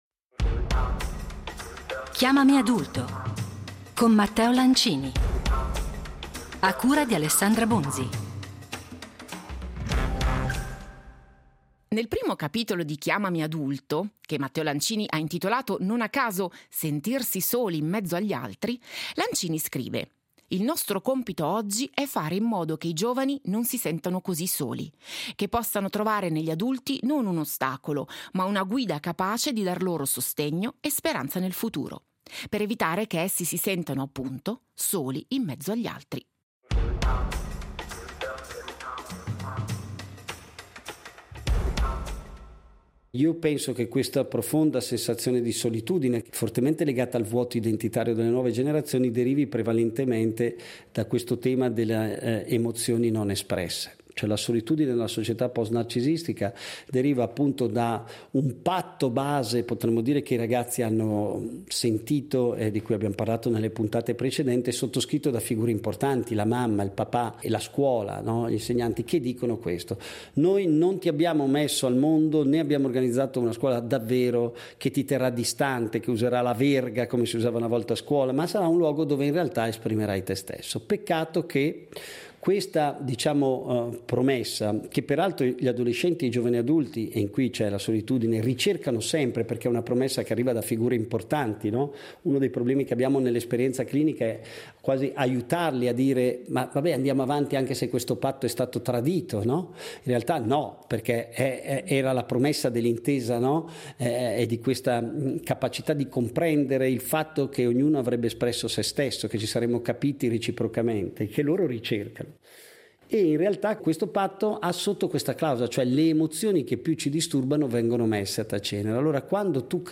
nel suo studio di Milano